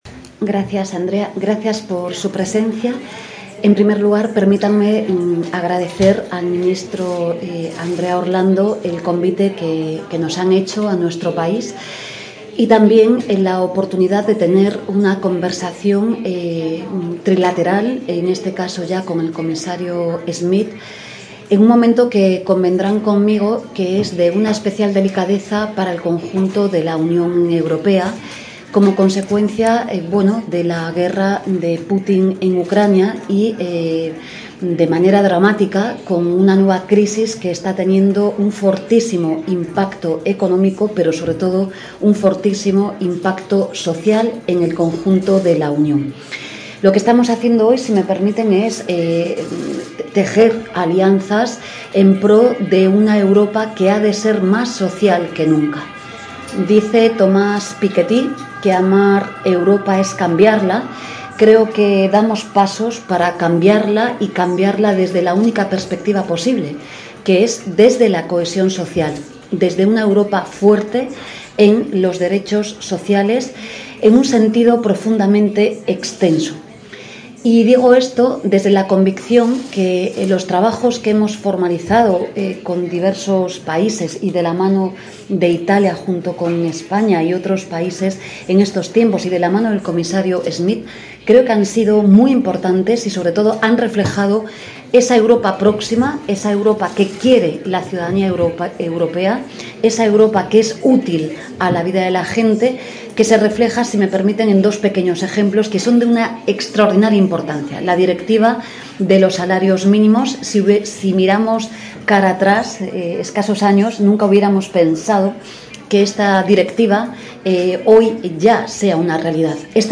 Rueda de prensa Yolanda Diaz Roma.mp3